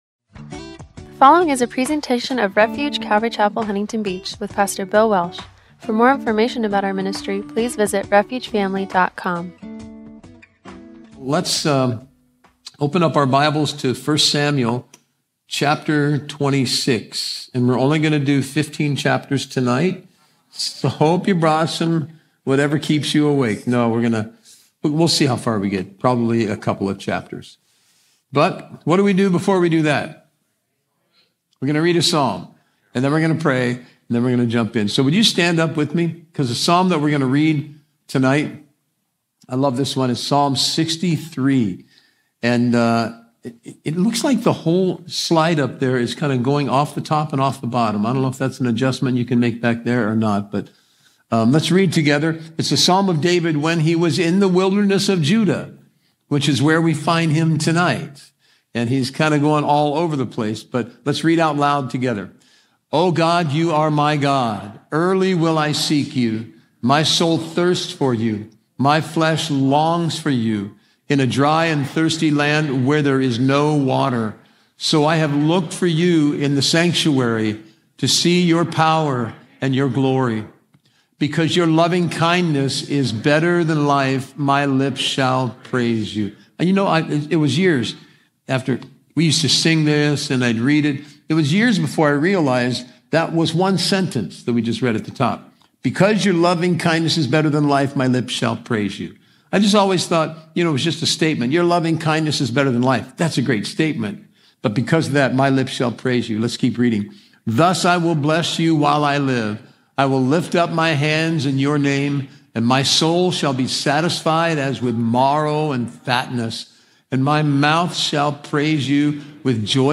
“David: Still on the Run”-1 Samuel 26 – Audio-only Sermon Archive
Passage: 1 Samuel 26 Service Type: Wednesday Night